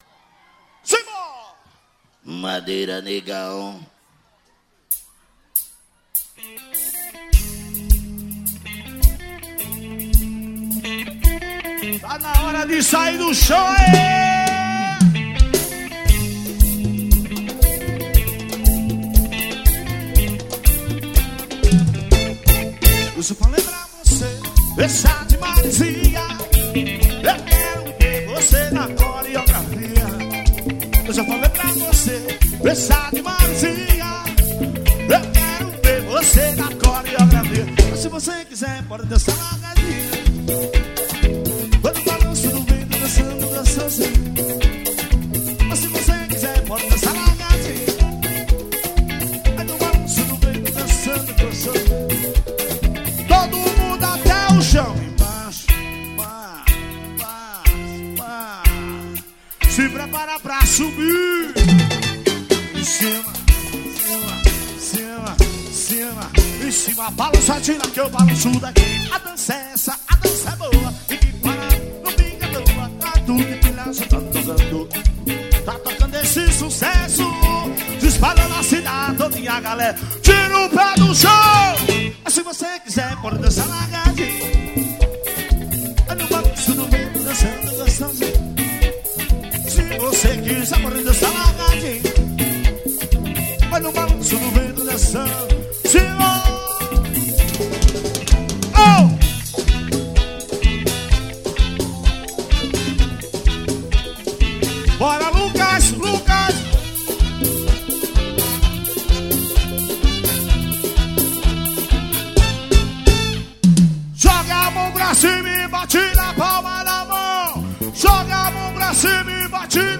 AXÉ.